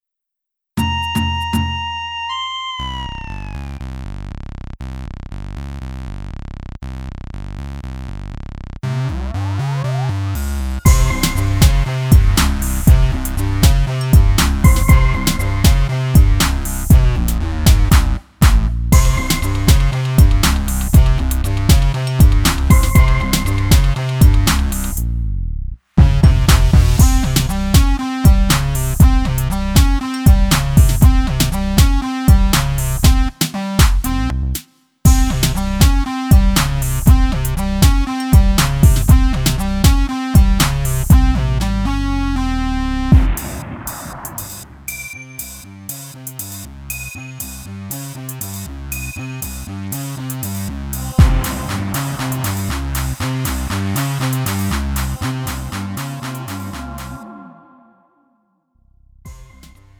음정 원키 3:17
장르 가요 구분 Lite MR